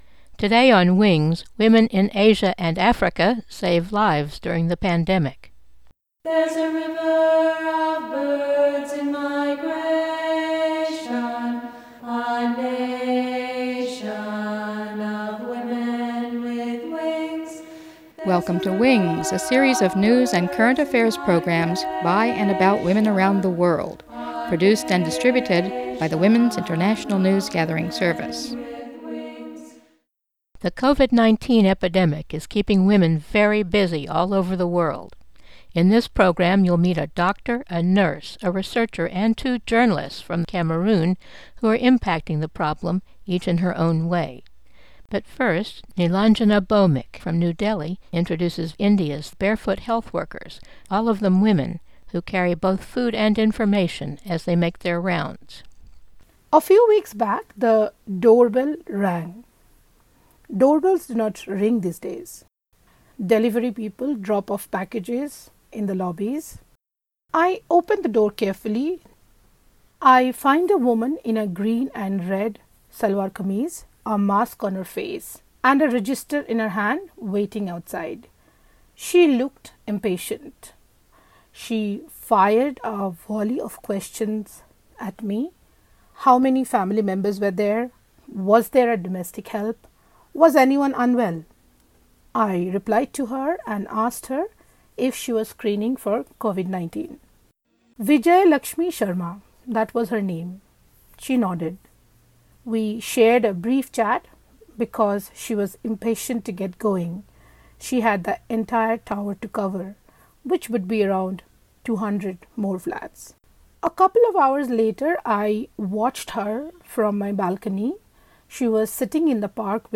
Reports from India and Cameroon